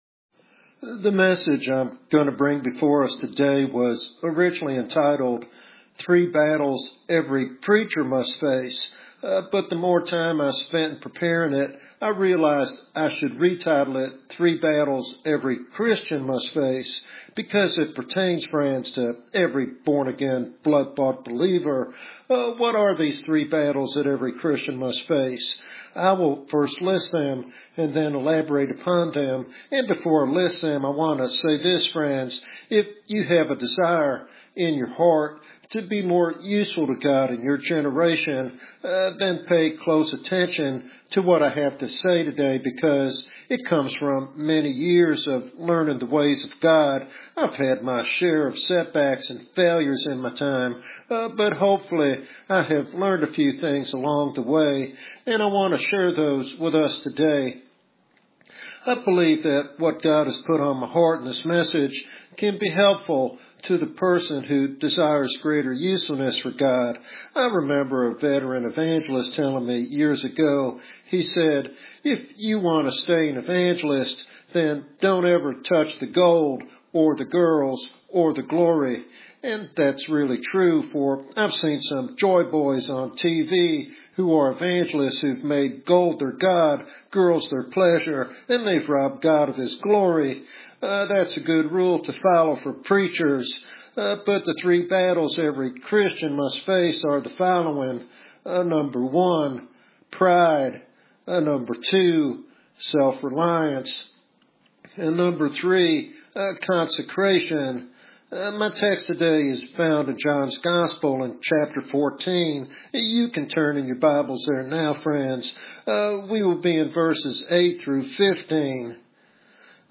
This sermon encourages Christians to pursue holiness and dependence on God to fulfill their divine calling.